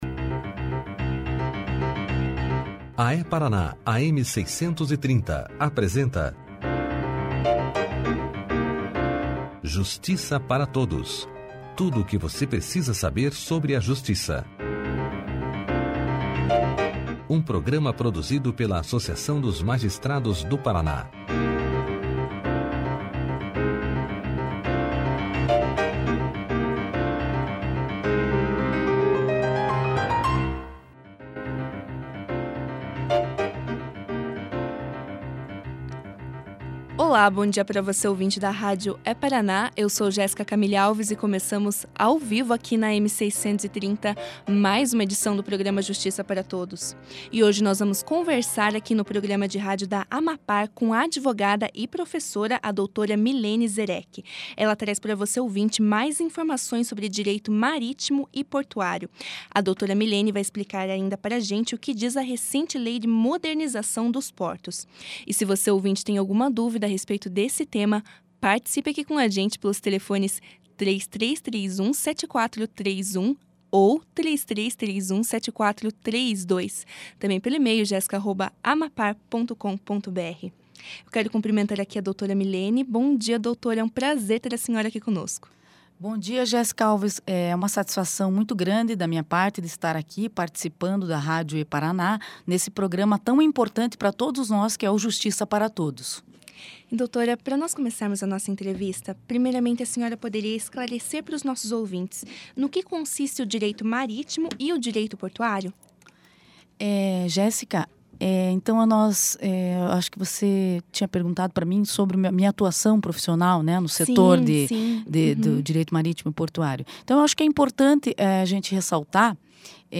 Escute aqui a entrevista na íntegra.